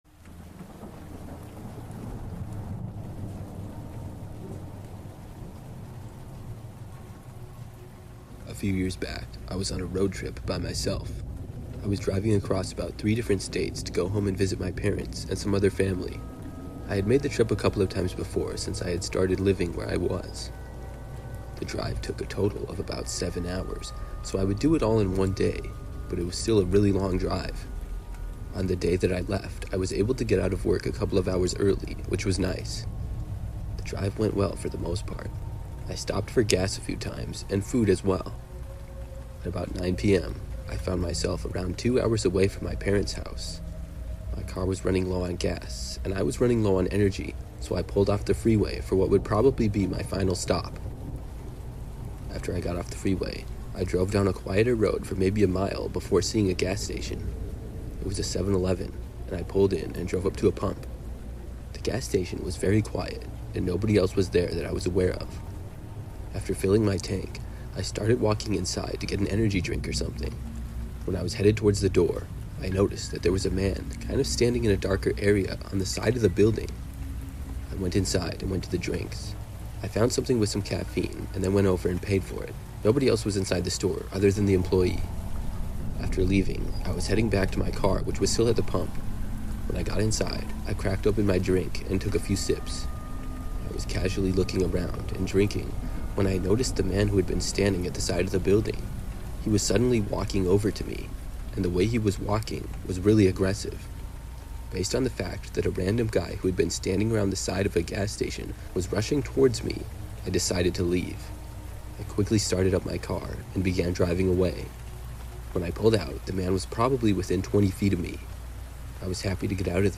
All advertisements are placed exclusively at the beginning of each episode, ensuring complete immersion in our horror stories without interruptions. Experience uninterrupted psychological journeys from start to finish with zero advertising breaks.